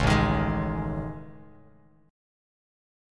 AM#11 chord